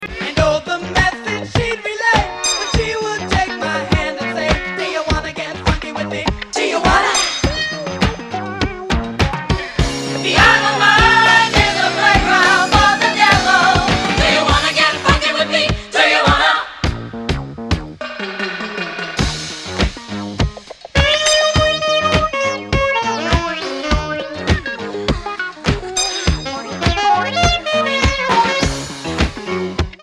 Classic 2-Step-Boogie / Disco reissue EP!